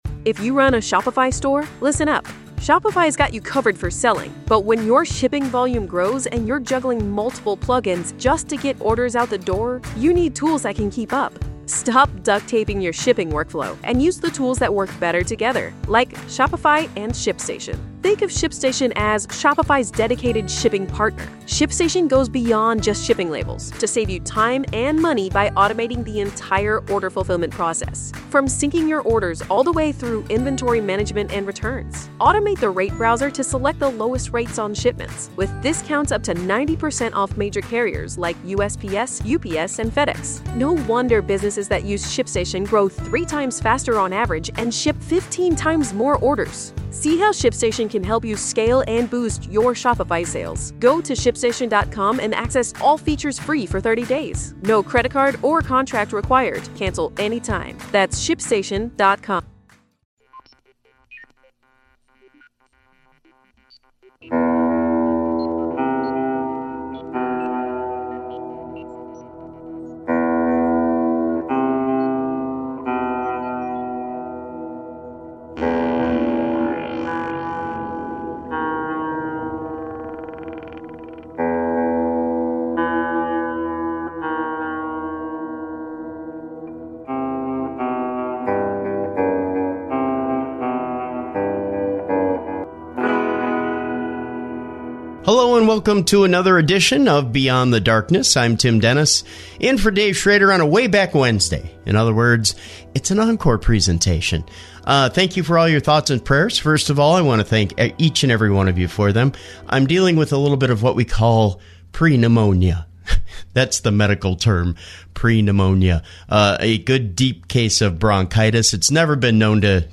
In the second half of the show, the King of Late Night Paranormal Radio, and the host of Coast to Coast AM, George Noory, joins the program. He expounds on his life experiences, paranormal theories and experiences, and where he believes things are headed!